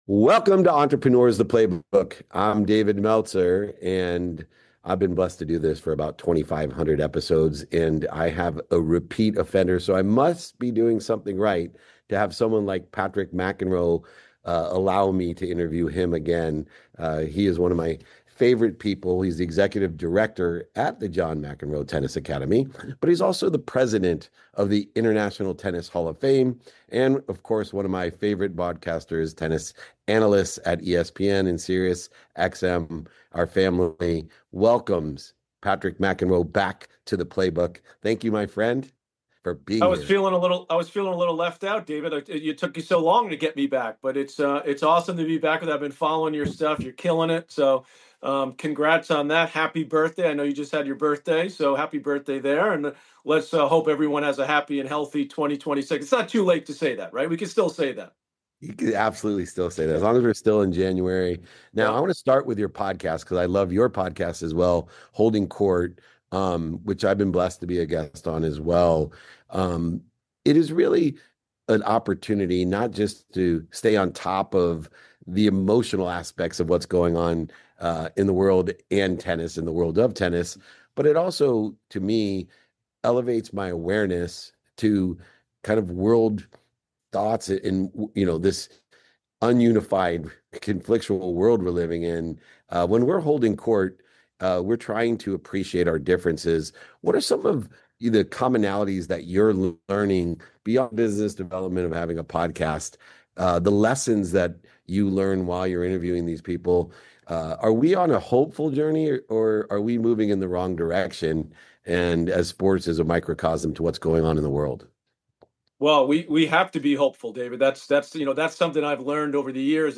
In today’s episode, I sit down with Patrick McEnroe, former professional tennis player, longtime broadcaster, and president of the International Tennis Hall of Fame. We talk about what years in elite sports teach you about pressure, patience, and personal growth, especially for young athletes navigating expectations shaped by social media. Patrick shares lessons from coaching kids, parenting teenagers, and competing at the highest level, including how listening changes performance, why small goals matter more than wins, and how to separate identity from outcomes.